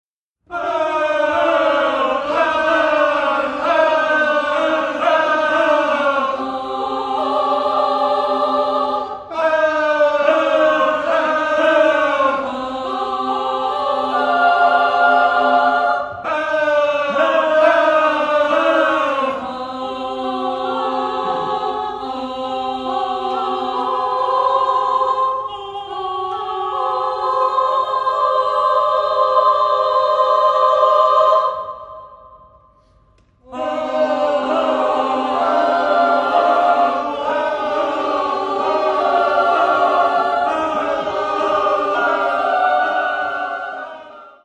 pro amatérský smíšený sbor
Etuda V stojí na glissandech, zpívá se na neurčitý vokál.